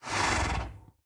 Minecraft Version Minecraft Version snapshot Latest Release | Latest Snapshot snapshot / assets / minecraft / sounds / mob / horse / idle3.ogg Compare With Compare With Latest Release | Latest Snapshot